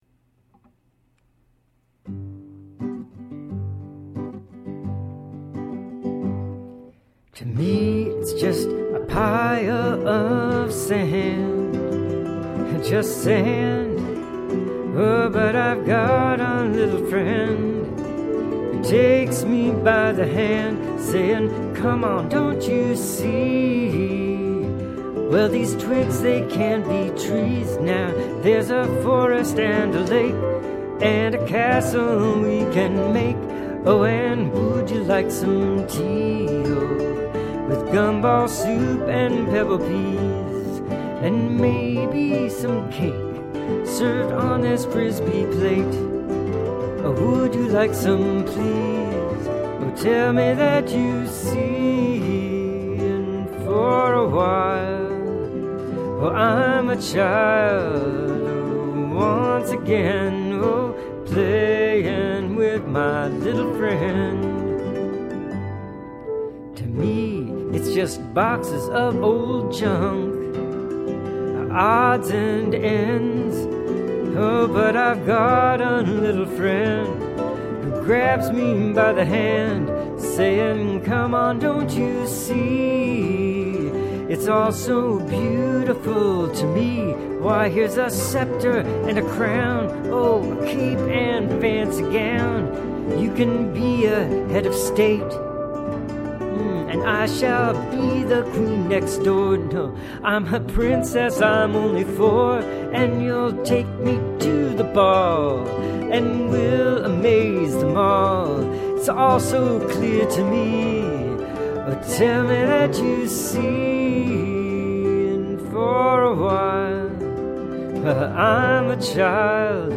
Solo Vocals and Hammered Dulcimer, Guitar, and ceramic flute